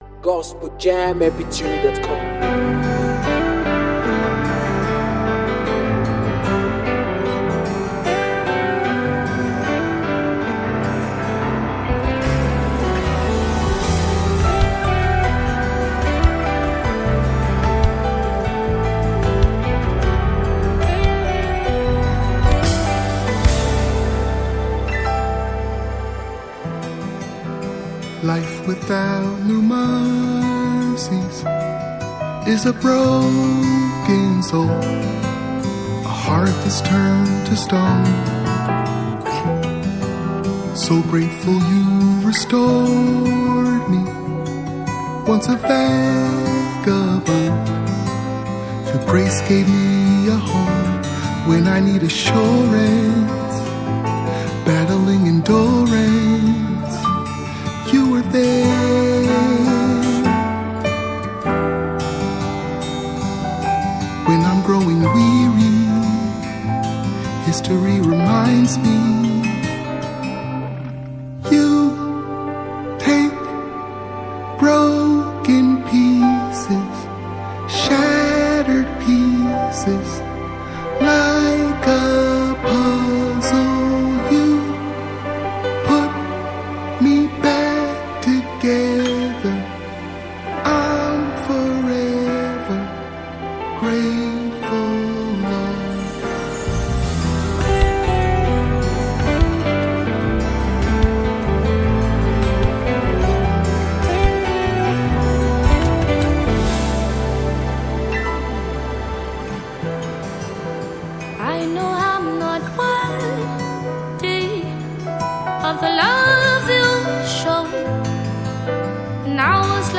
deeply emotional and worshipful gospel song
soulful voice and passionate delivery